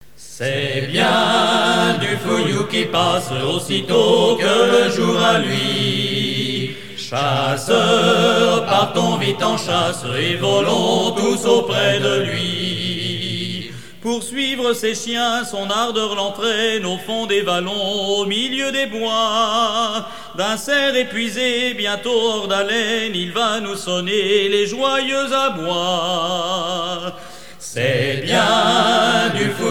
fanfare
rencontre de sonneurs de trompe
Pièce musicale éditée